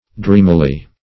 Dreamily \Dream"i*ly\ (dr[=e]m"[i^]*l[y^]), adv.